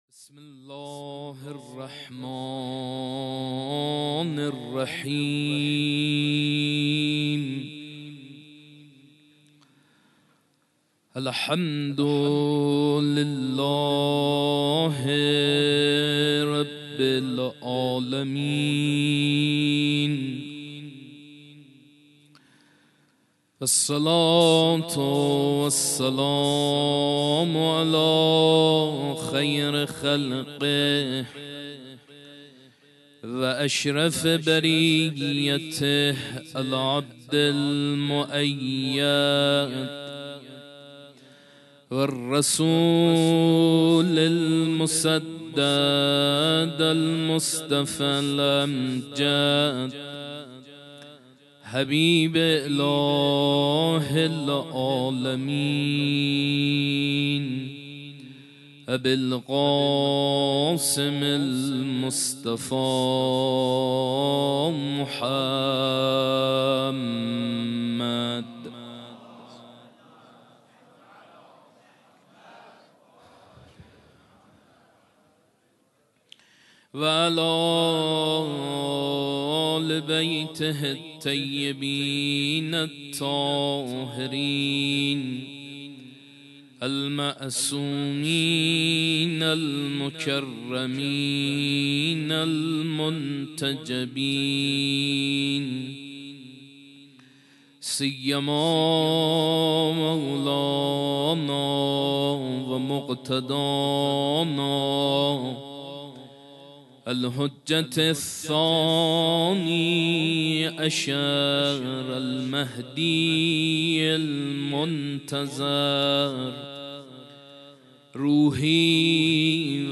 سخنرانی
حسینیه انصارالحسین (ع) - شب قدر